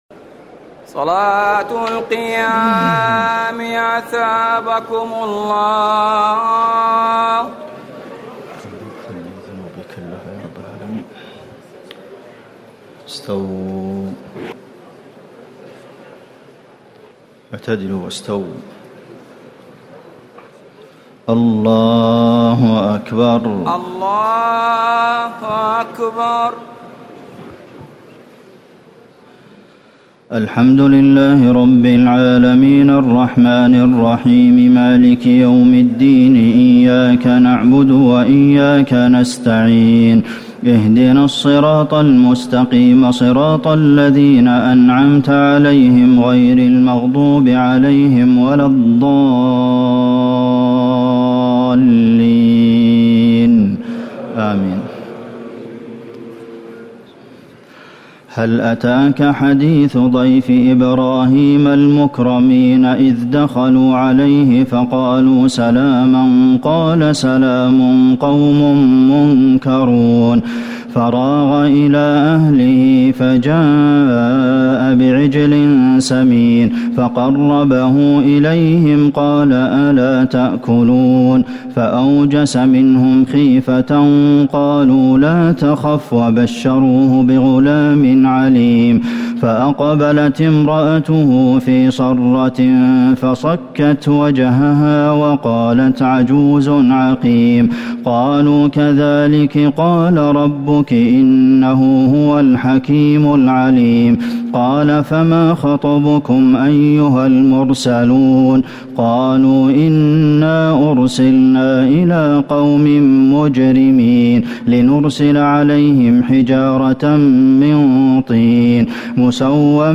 تراويح ليلة 26 رمضان 1439هـ من سور الذاريات(24-60) و الطور و النجم و القمر Taraweeh 26 st night Ramadan 1439H from Surah Adh-Dhaariyat and At-Tur and An-Najm and Al-Qamar > تراويح الحرم النبوي عام 1439 🕌 > التراويح - تلاوات الحرمين